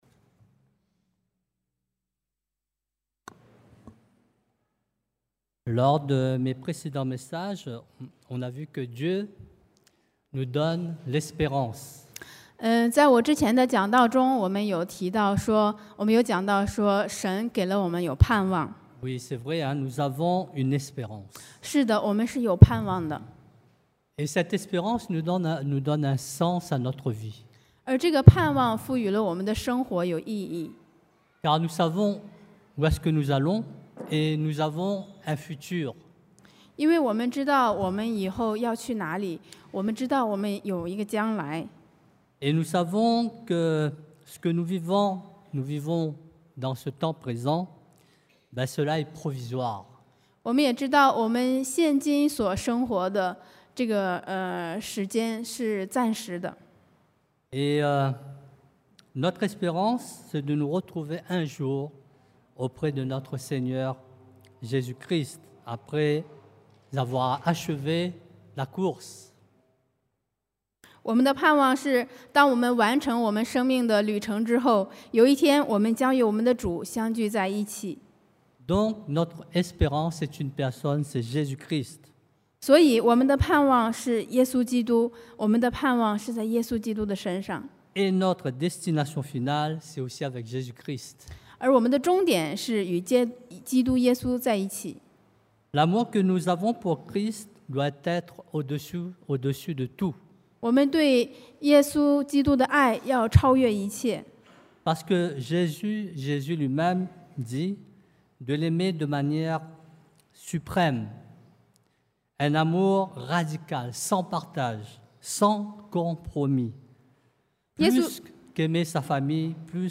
Être conforme à Jesus-Christ 效法耶穌基督 – Culte du dimanche
Type De Service: Predication du dimanche